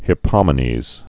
(hĭ-pŏmə-nēz)